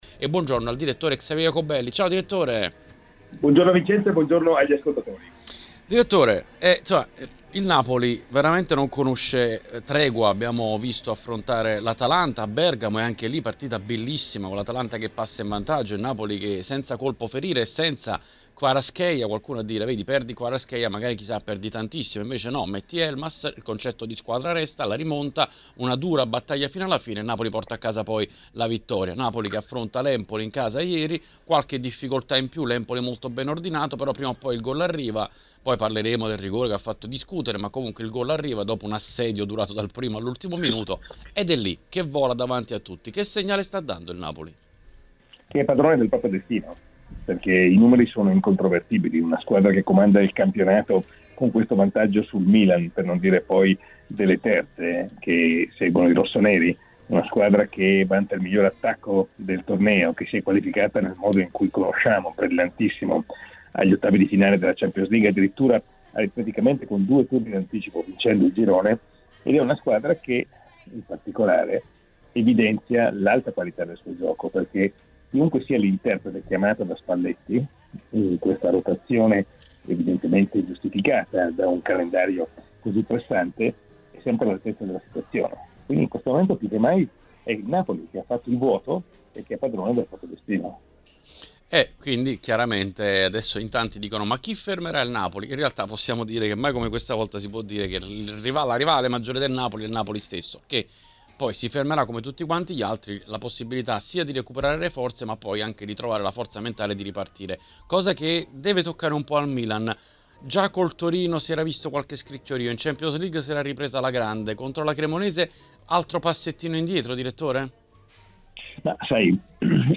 Durante l'appuntamento di oggi con L'Editoriale è intervenuto ai microfoni di TMW Radio Xavier Jacobelli.